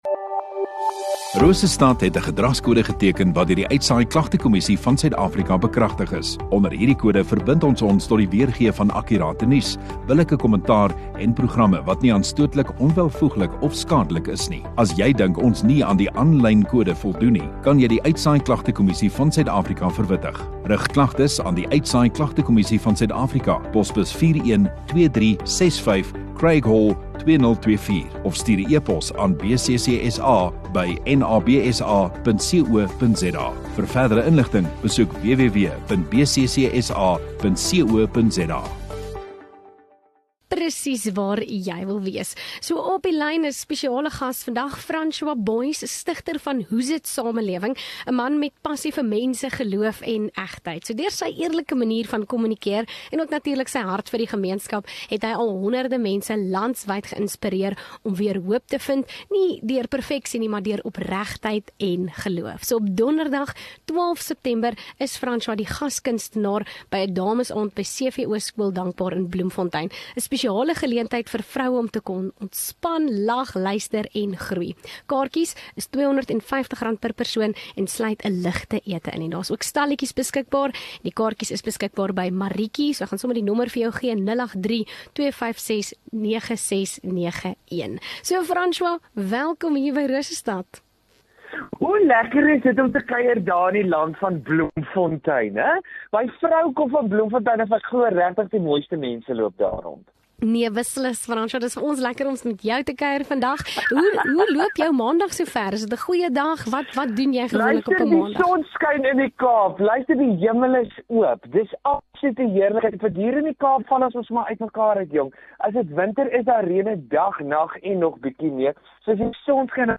Radio Rosestad View Promo Continue Radio Rosestad Install Kunstenaar Onderhoude 18 Aug Kunstenaar